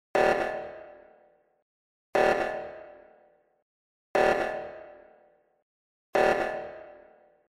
9. Сирена
9-sirena.mp3